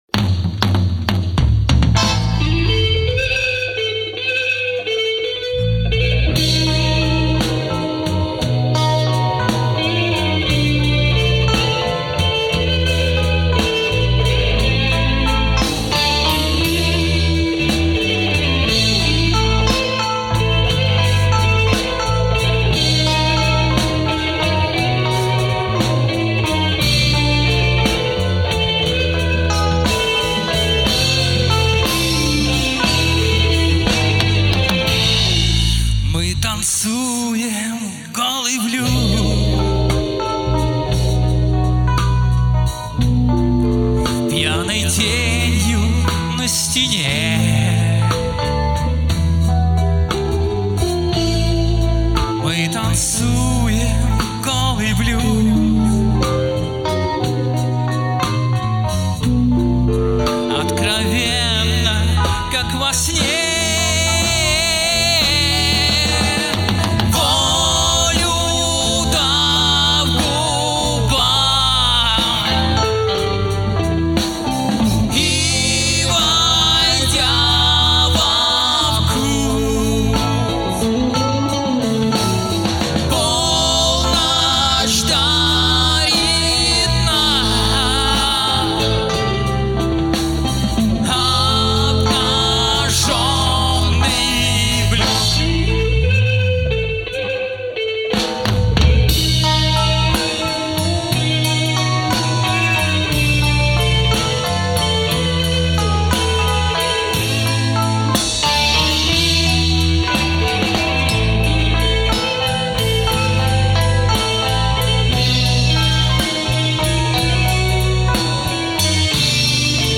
Потому не судите строго-это не студийные вылизанные записи-это как в жизни- по всякому.